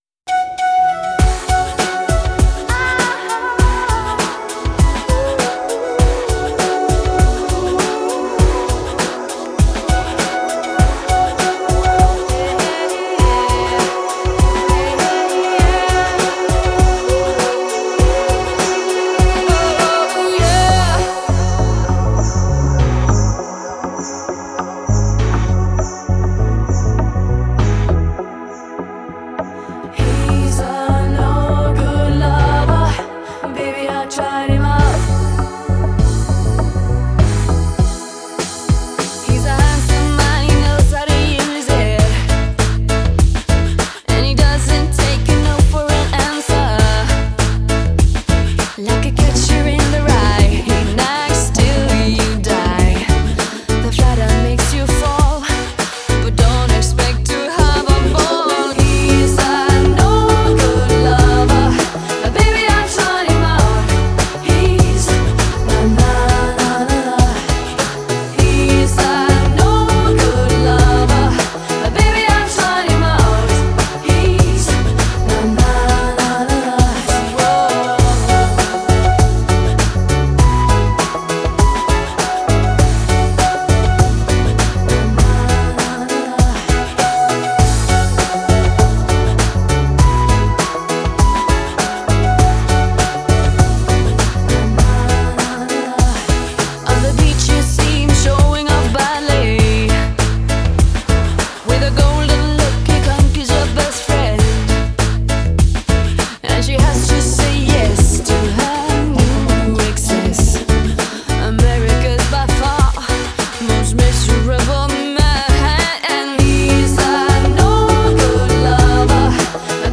Genre: Electronic, Pop
Style: Eurodance